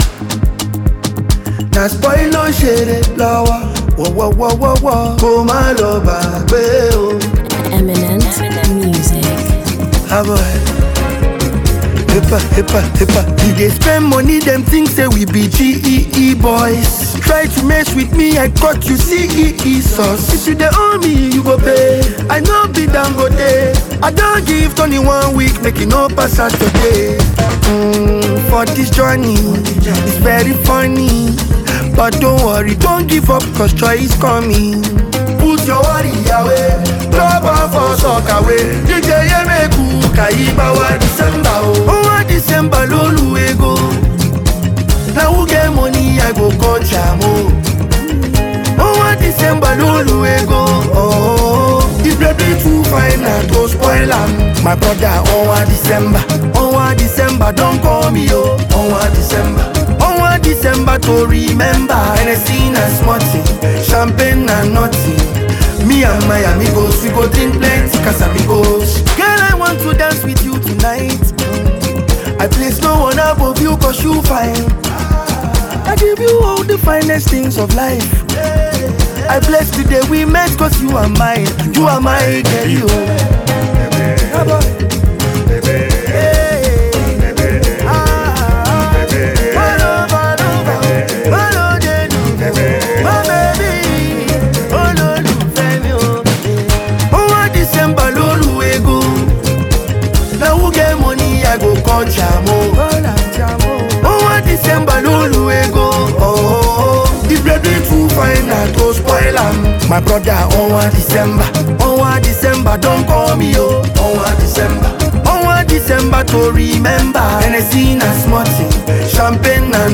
festive Afrobeats single